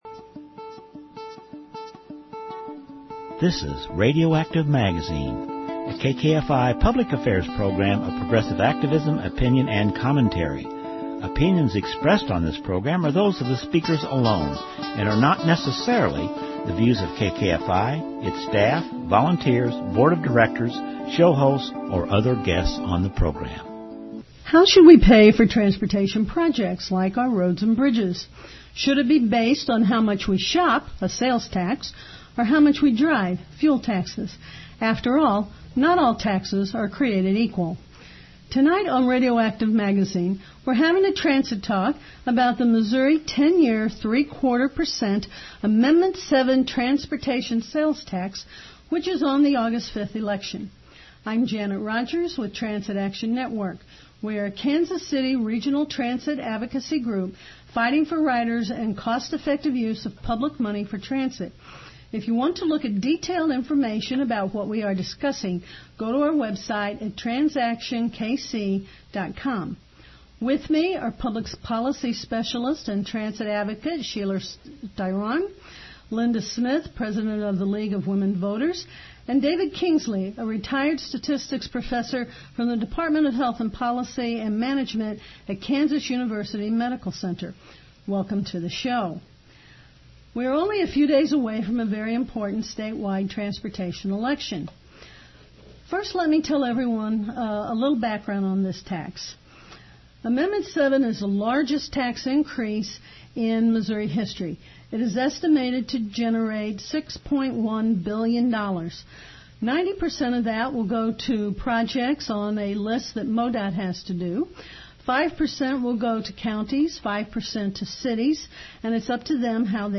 Where: KKFI 90.1 FM Community Radio